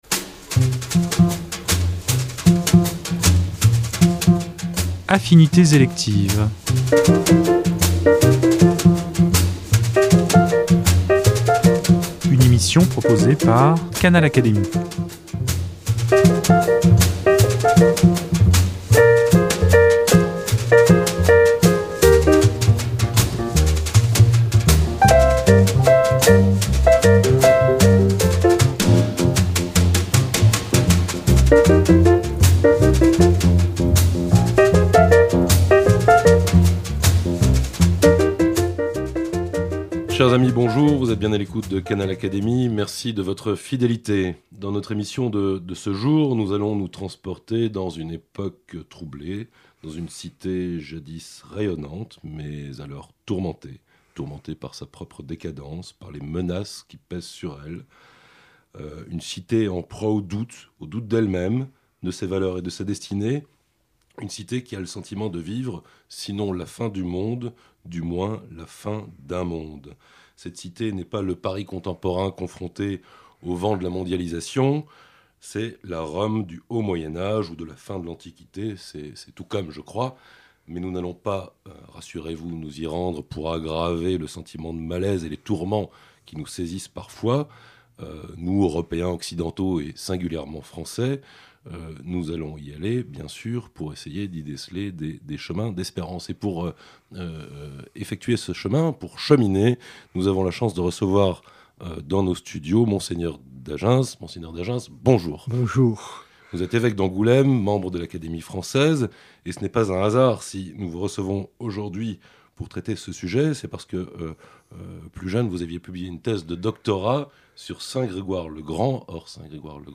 C’est du moins la conviction de Mgr Dagens, de l’Académie française, et auteur d’une thèse universitaire sur ce pape, qui, dans un entretien inédit, évoque aussi bien les circonsta